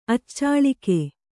♪ accāḷike